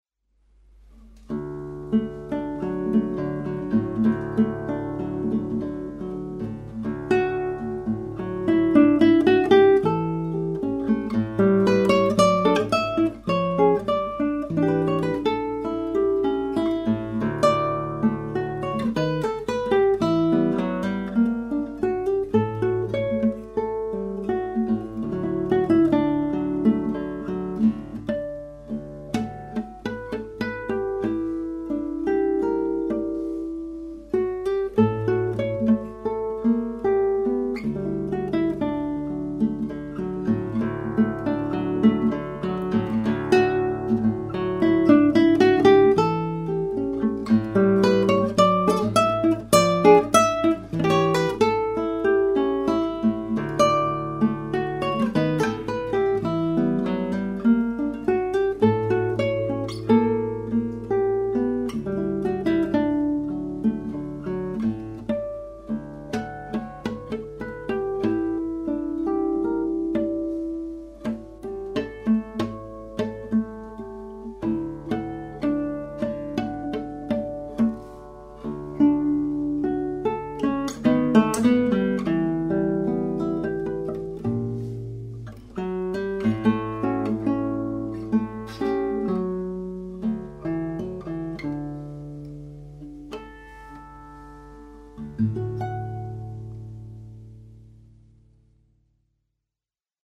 Cançon de lladre, chanson Catalane transcrite par Llobet
Ce dernier mixage est excellent!!!
J'en préfère la clarté et il fait mieux ressortir la luminuosité extraordinaire de ce morceau limpide.